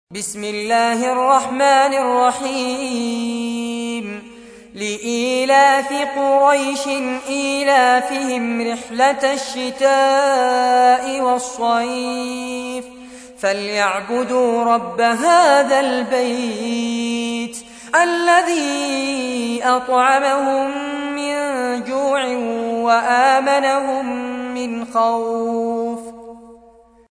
تحميل : 106. سورة قريش / القارئ فارس عباد / القرآن الكريم / موقع يا حسين